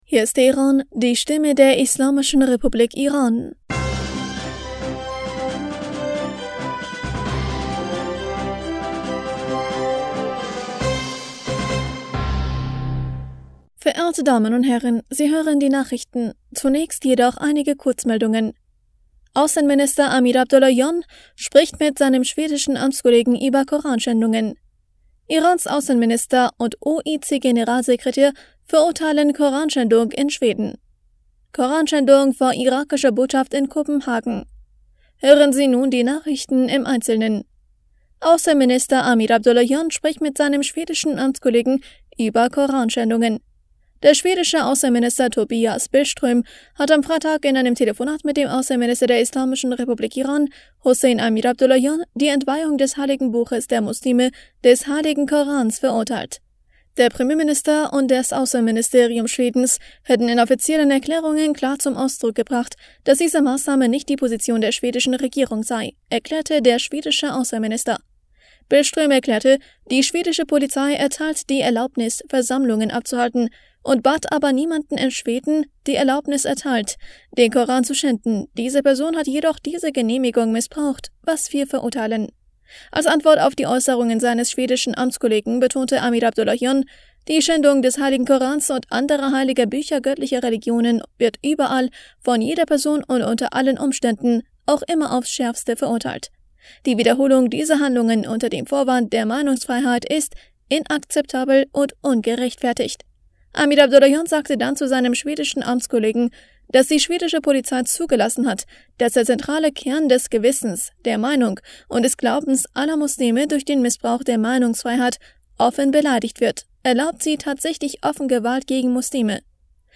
Nachrichten vom 22. Juli 2023